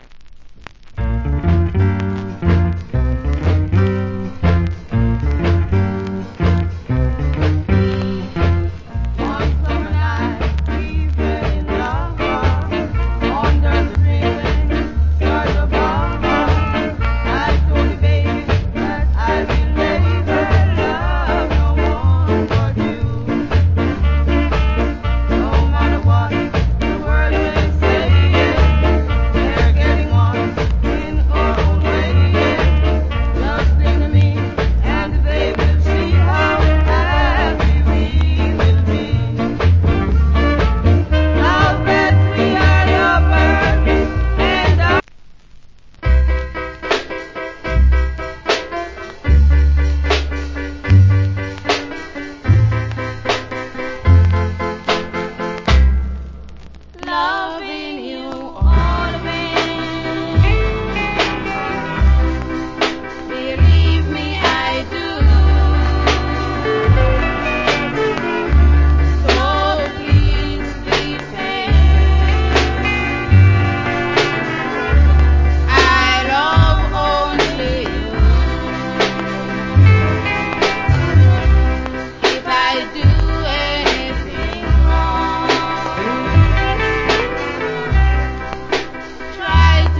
Nice Duet Ska Vocal.